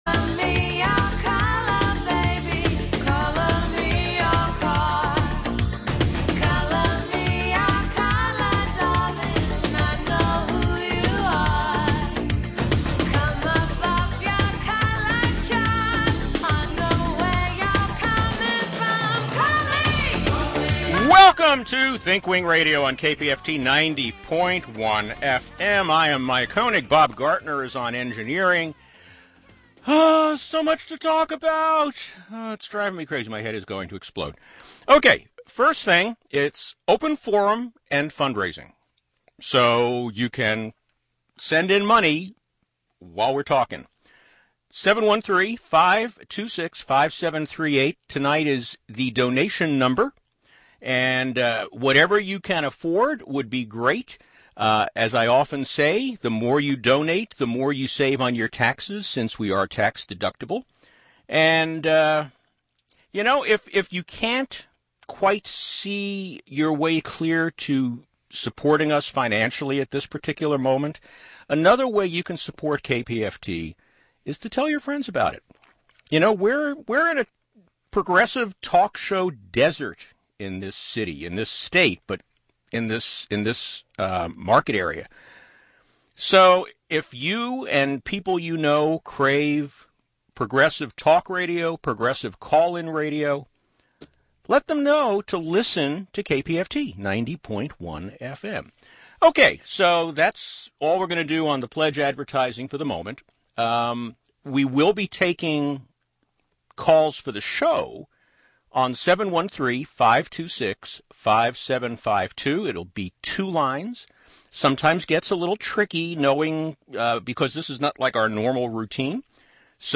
We take callers during this show.